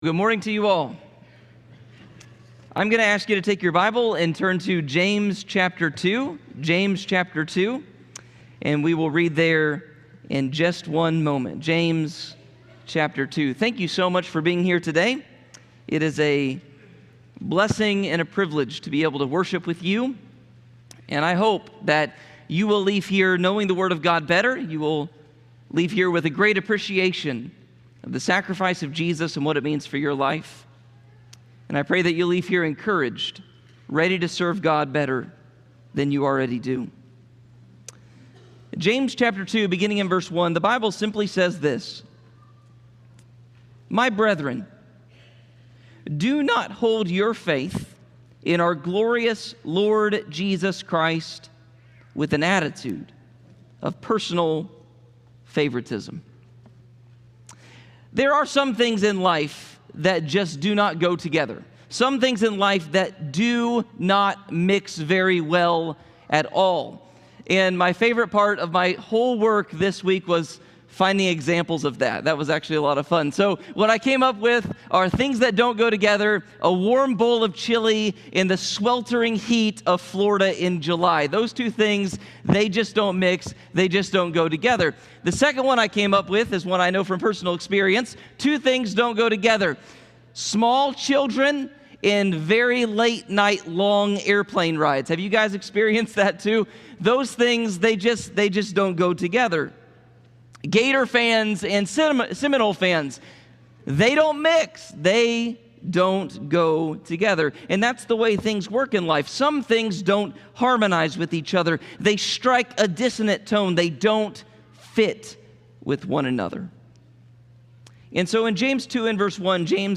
Sermons Is My Love Complete?